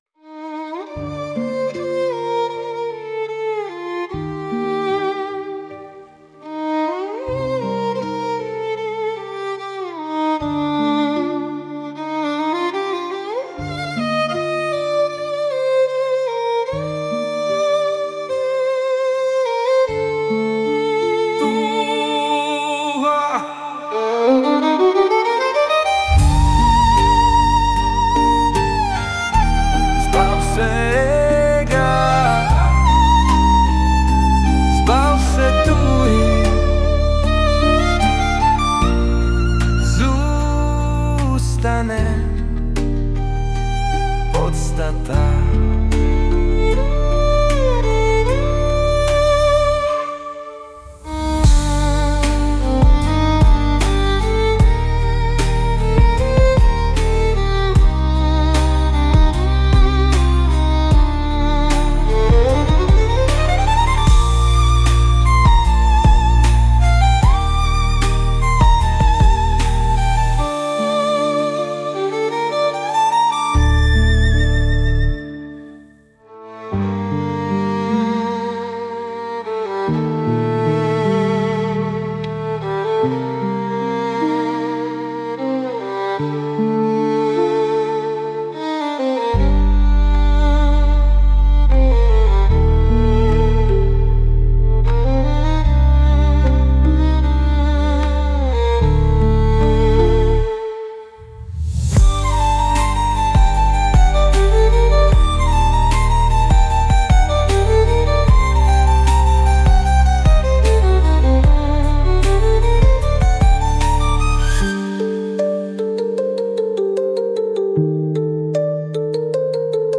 2005 & Hudba, Zpěv a Obrázek: AI